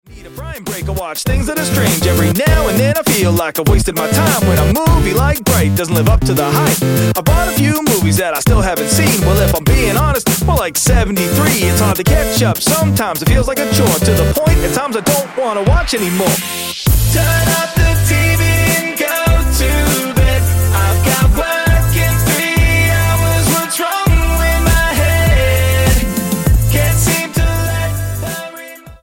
STYLE: Hip-Hop
intentionally simple old skool vocal flows